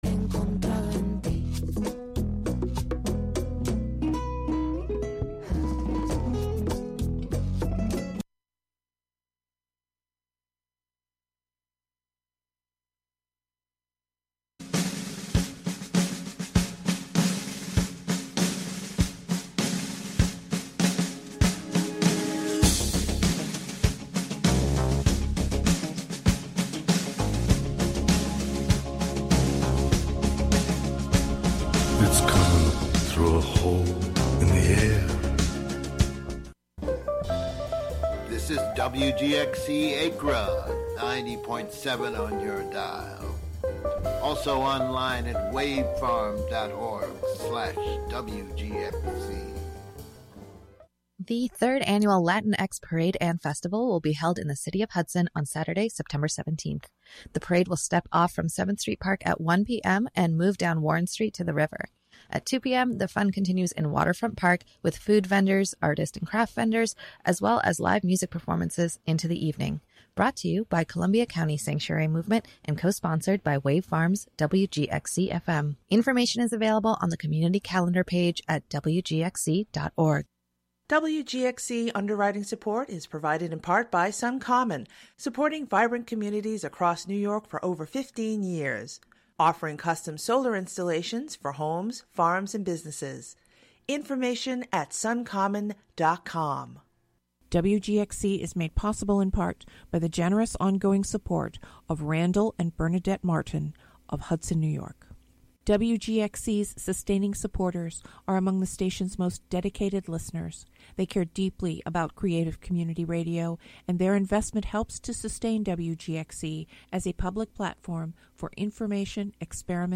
Music, talk, and schtick, just like any variety show.